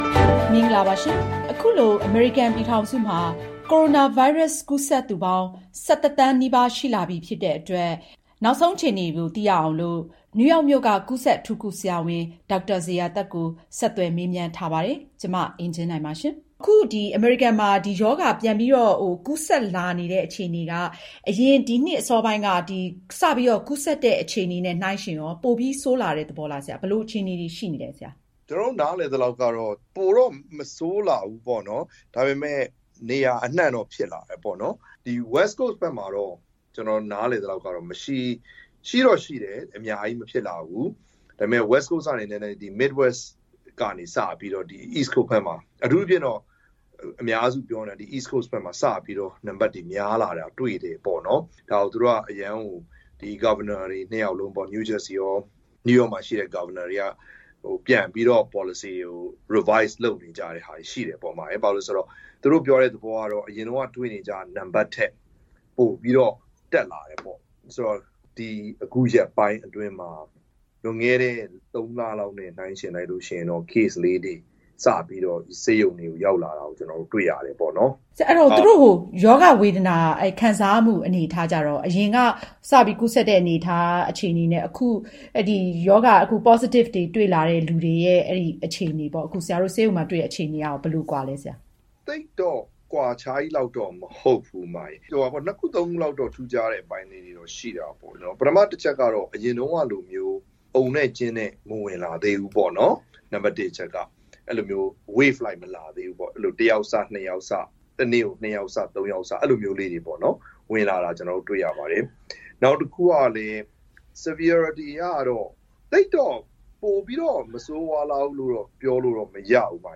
ဆက်သွယ်မေးမြန်းခန်း